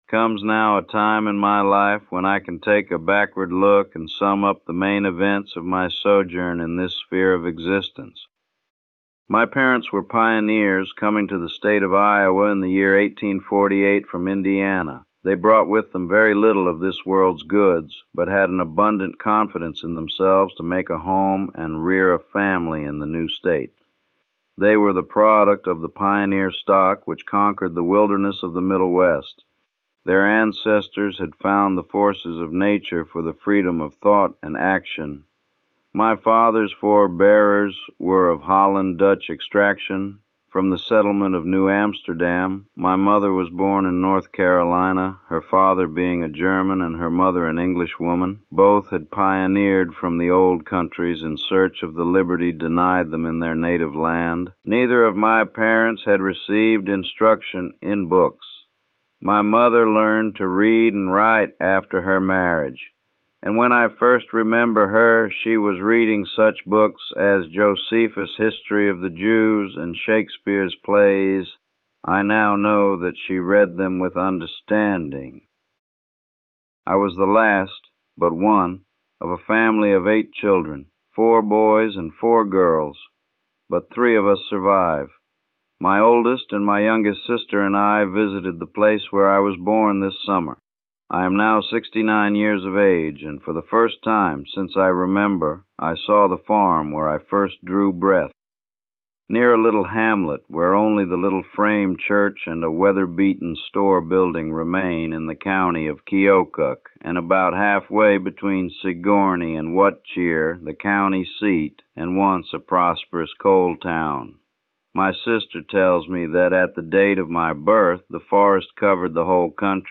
Narrated by AI-Generated Historical Voice